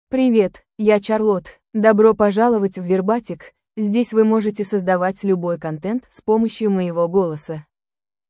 FemaleRussian (Russia)
Charlotte — Female Russian AI voice
Voice sample
Listen to Charlotte's female Russian voice.
Charlotte delivers clear pronunciation with authentic Russia Russian intonation, making your content sound professionally produced.